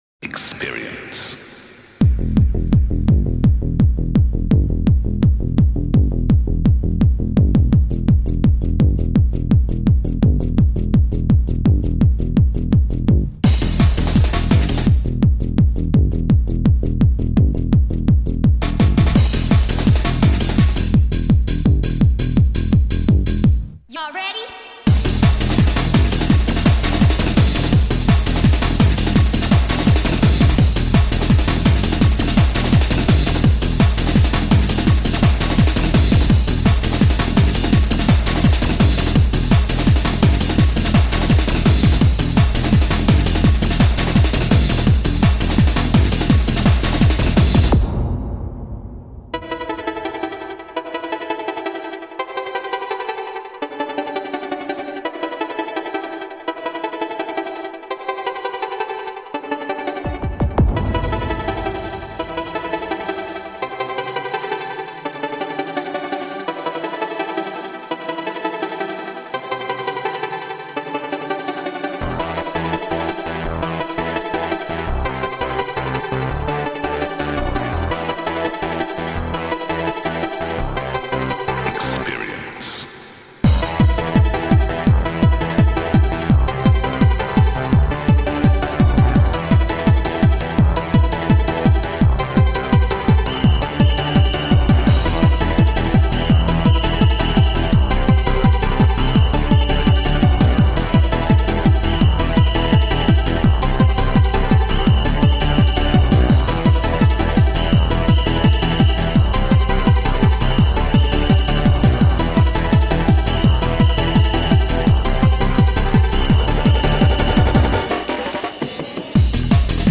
Жанр: Dance